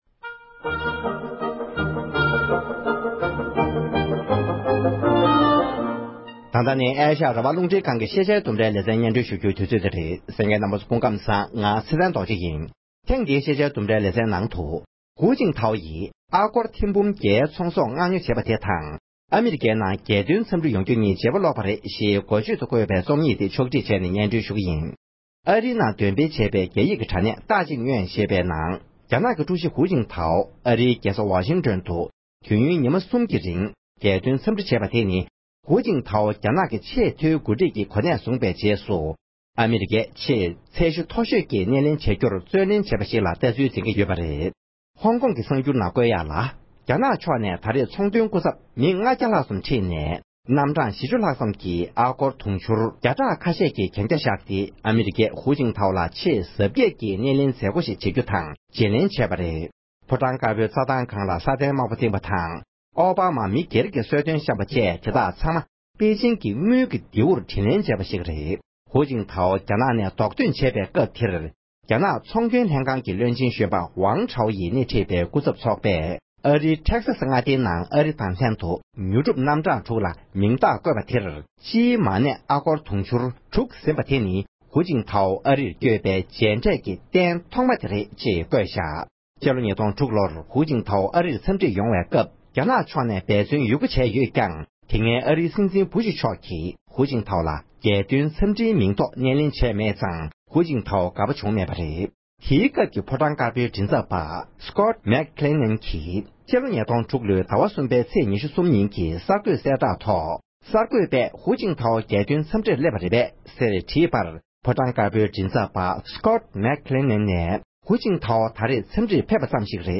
ཕབ་བསྒྱུར་དང་སྙན་སྒྲོན་ཞུས་པར་གསན་རོགས༎